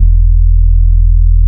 808 6.wav